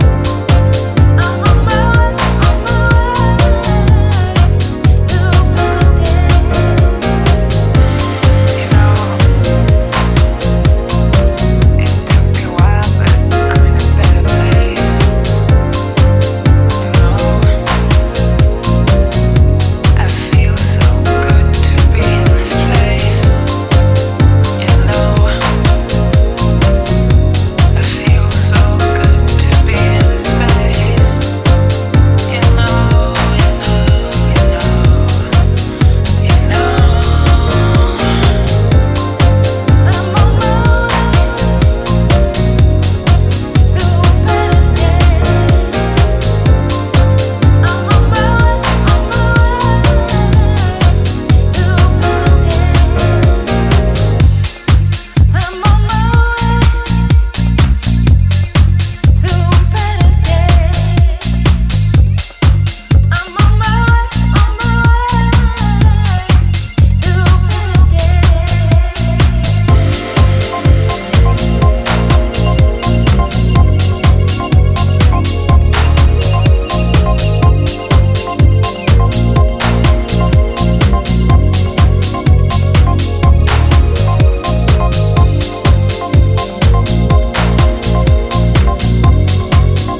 ジャジーなスウィングが効いたファンキー・ディープ・ハウス！